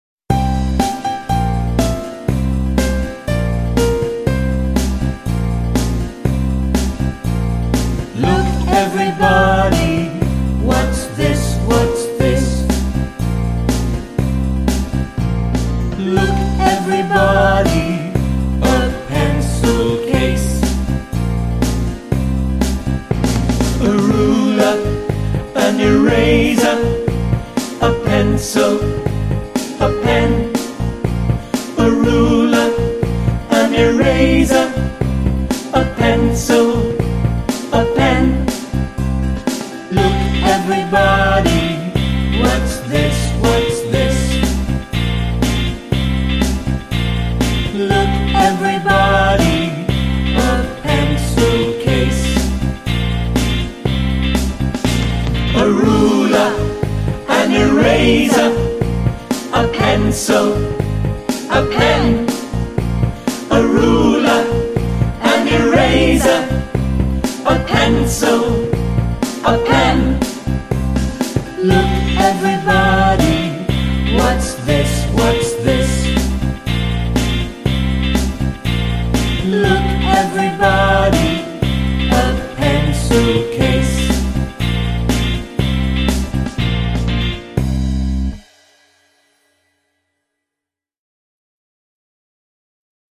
Песенка для изучения английского языка для маленьких детей.